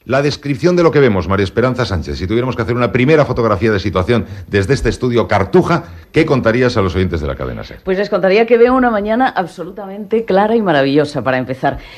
Programa especial fet des de l'Exposició Universal de Sevilla de 1992 el dia de la seva inauguració
Info-entreteniment